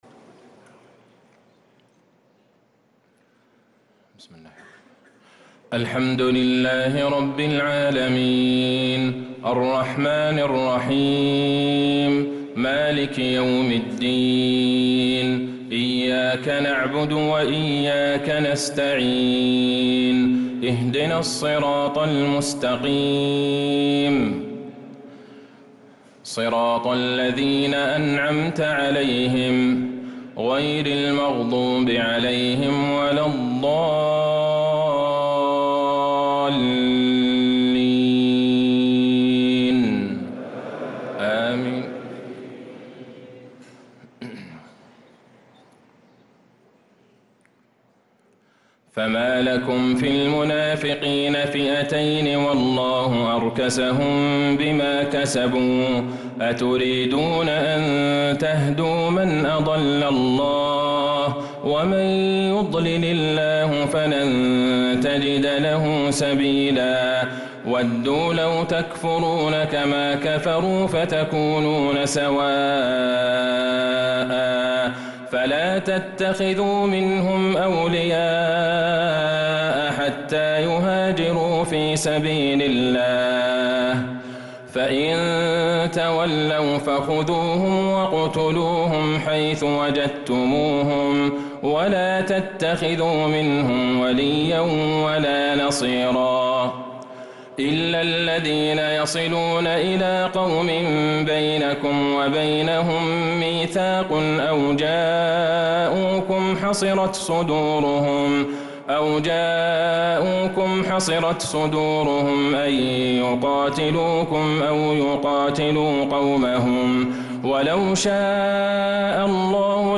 تراويح ليلة 7 رمضان 1446هـ من سورة النساء {88-134} Taraweeh 7th night Ramadan 1446H Surah An-Nisaa > تراويح الحرم النبوي عام 1446 🕌 > التراويح - تلاوات الحرمين